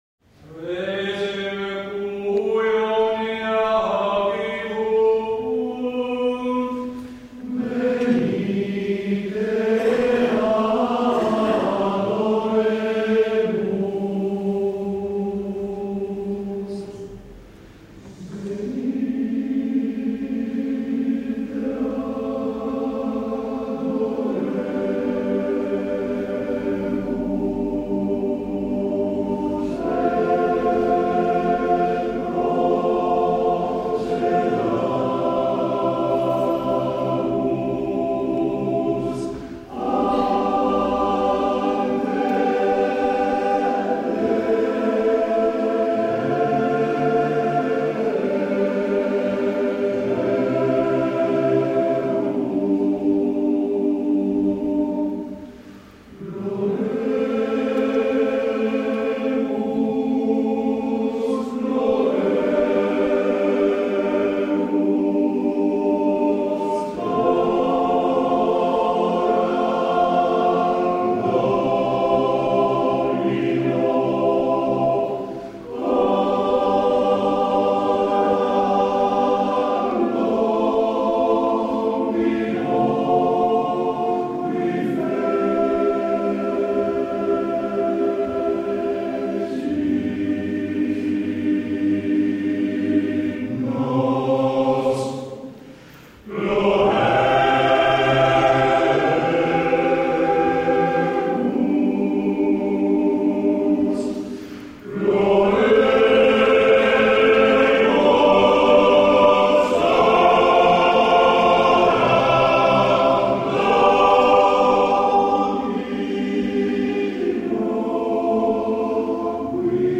Esecutore: Coro CAI Mariotti Parma